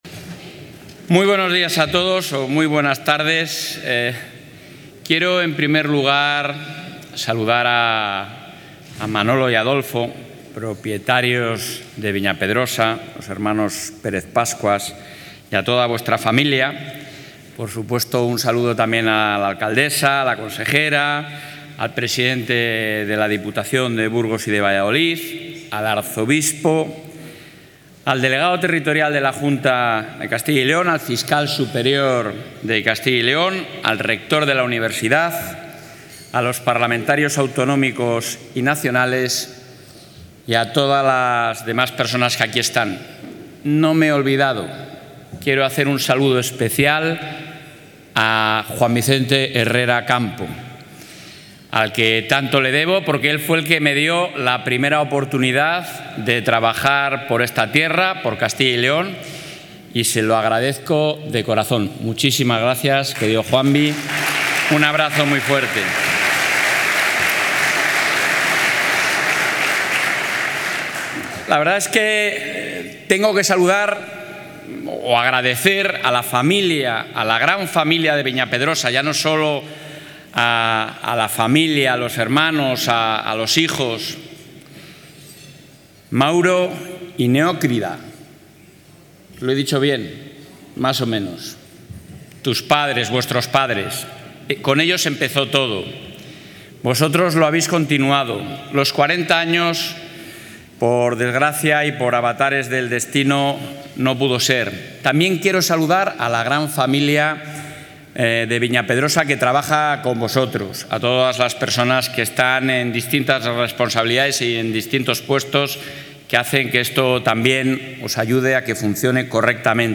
Intervención del presidente de la Junta.
Galería Multimedia Intervención del presidente de la Junta Acto 45 aniversario de 'Viña Pedrosa' Acto 45 aniversario de 'Viña Pedrosa' Acto 45 aniversario de 'Viña Pedrosa' Acto 45 aniversario de 'Viña Pedrosa'